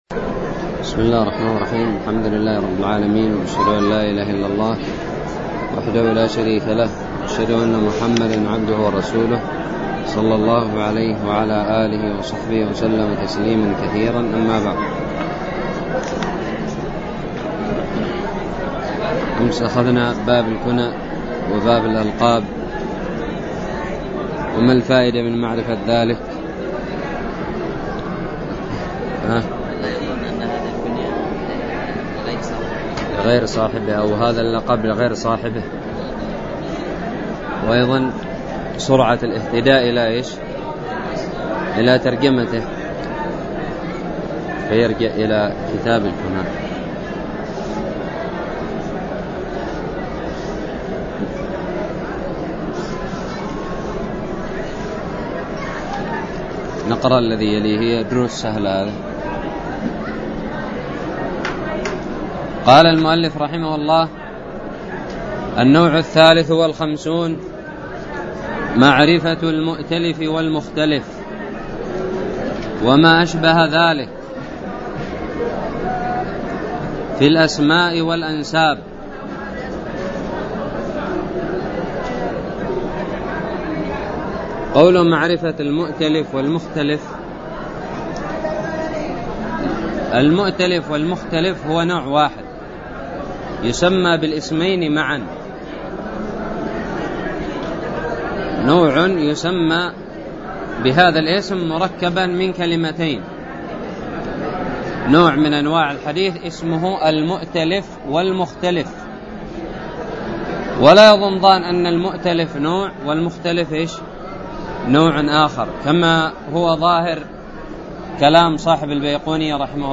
الدرس الرابع والخمسون من شرح كتاب الباعث الحثيث
ألقيت بدار الحديث السلفية للعلوم الشرعية بالضالع